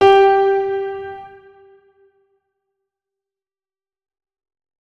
3098b9f051 Divergent / mods / Hideout Furniture / gamedata / sounds / interface / keyboard / piano / notes-43.ogg 58 KiB (Stored with Git LFS) Raw History